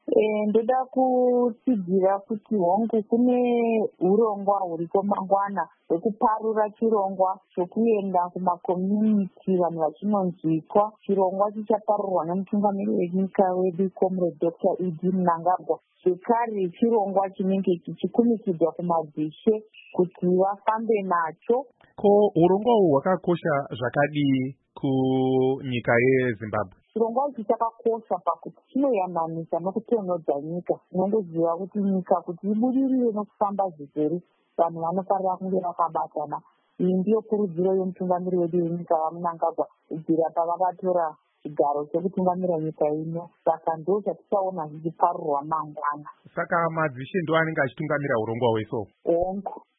Hurukuro naAmai Virginia Mabhiza